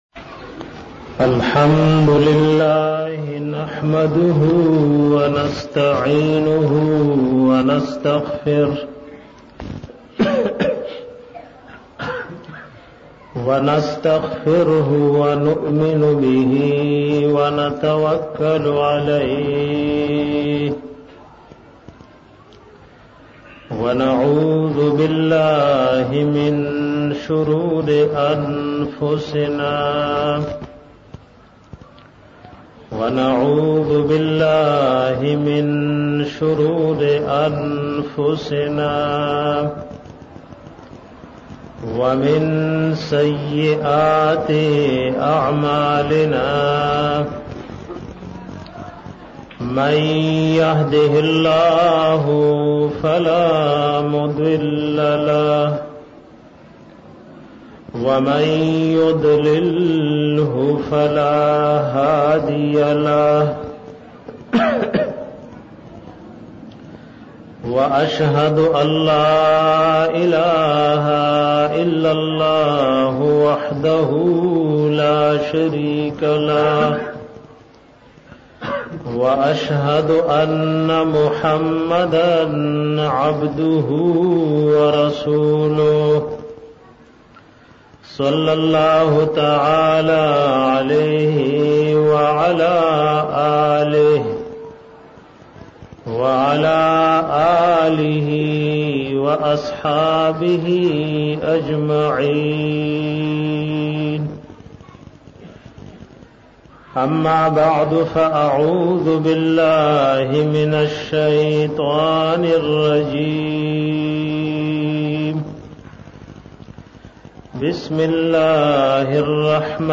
bayan da fazilat da qurani pak k 5